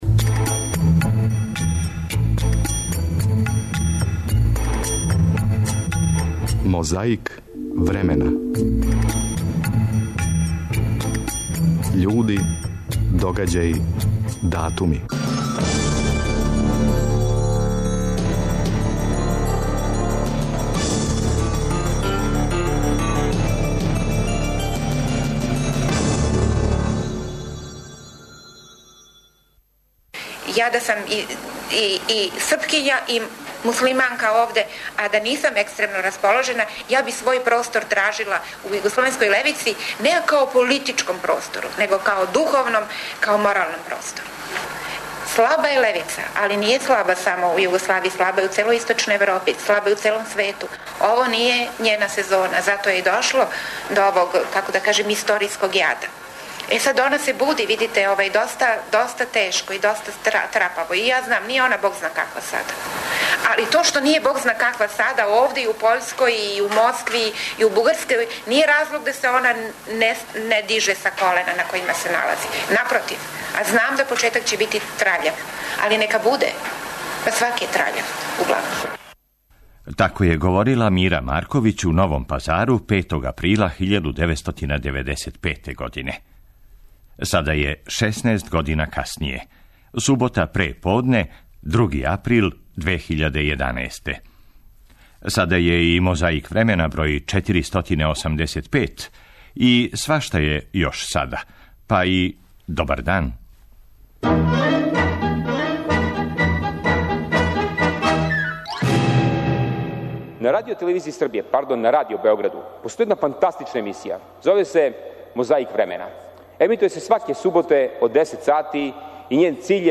Борбу против пилећег памћења почињемо говором Мире Марковић, 5. априла 1995. године у Новом Пазару.
Тада је Јосип Броз на Косову причао и причао...